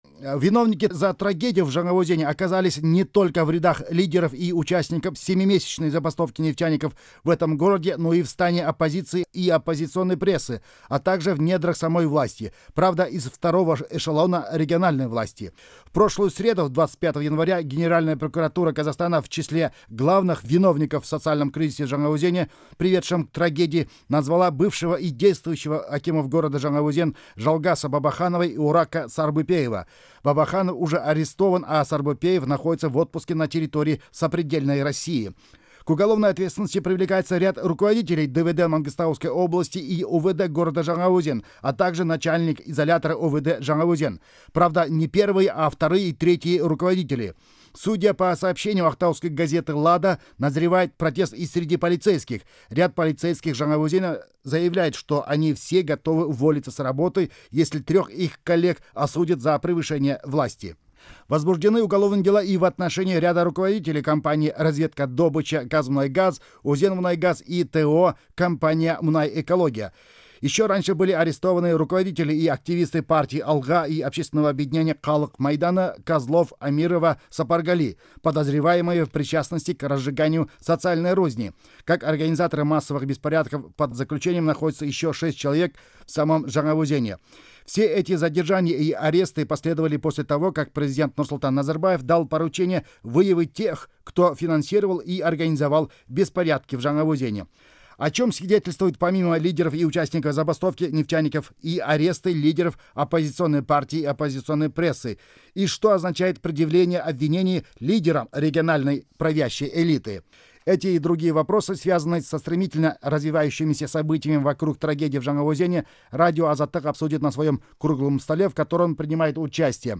Дөңгелек үстел: Жаңаөзенге байланысты шешім: жауаптылар мен жазалылар. 1 ақпан 2012 жыл.